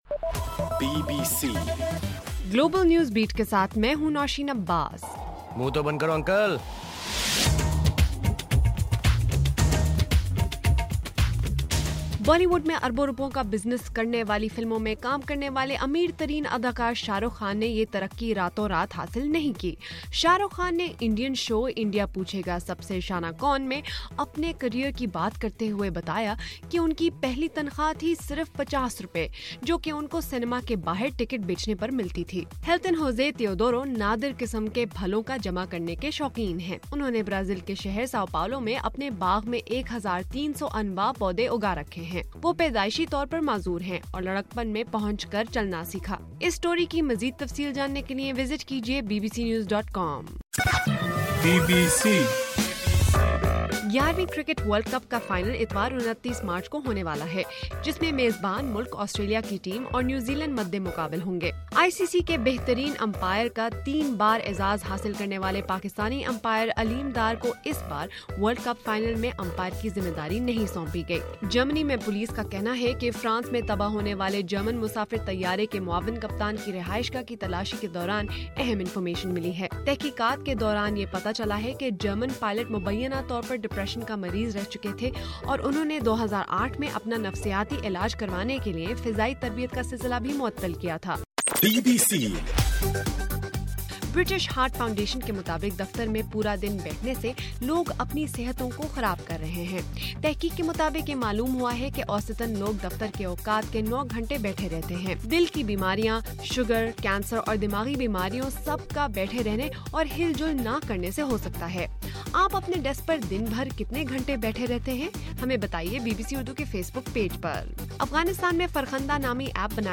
مارچ 27: رات 12 بجے کا گلوبل نیوز بیٹ بُلیٹن